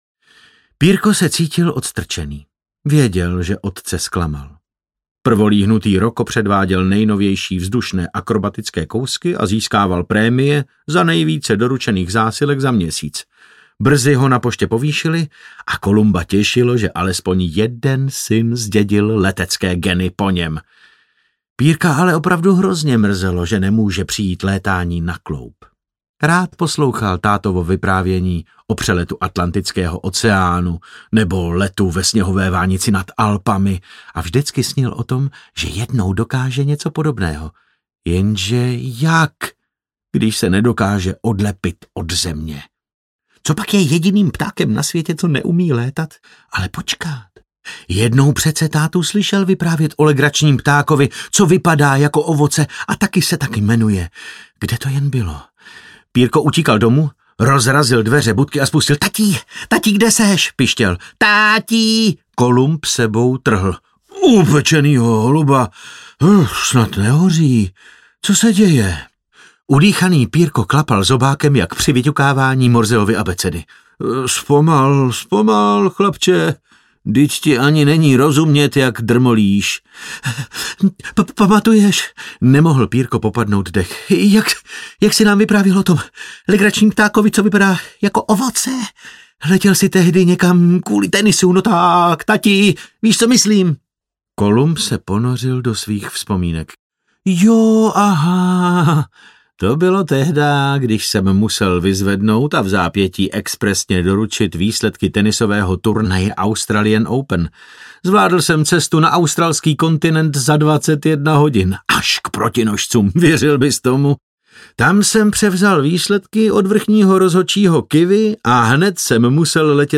Pilot Pírko audiokniha
Ukázka z knihy
• InterpretDavid Novotný